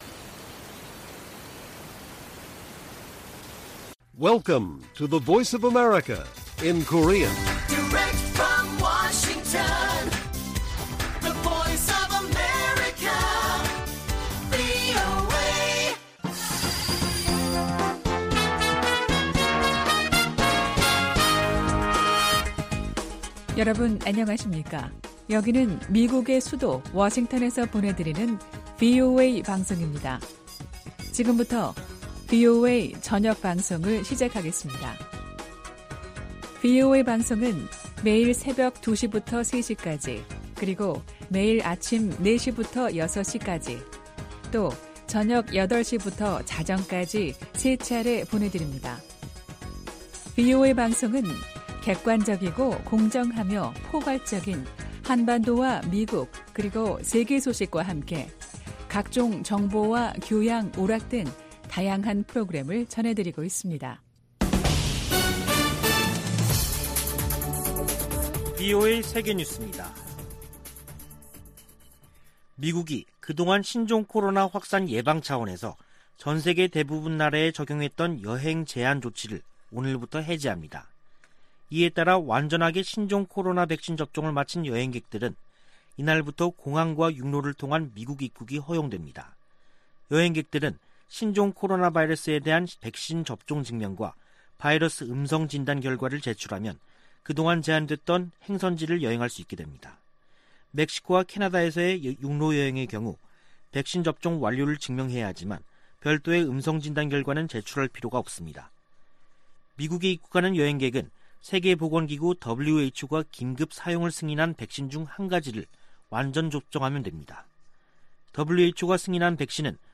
VOA 한국어 간판 뉴스 프로그램 '뉴스 투데이', 2021년 11월 8일 1부 방송입니다. 북한의 광범위한 인권 침해를 규탄하고 책임 추궁과 처벌을 촉구하는 올해 유엔 결의안 초안이 확인됐습니다. 미 국제개발처(USAID)가 북한 내 인권과 인도적 상황에 깊은 우려를 나타냈습니다. 중국과 북한 당국이 협력해 북한 국적 기독교인들을 색출하고 있다고 미국의 기독교 단체인 ‘가정연구협회’가 밝혔습니다.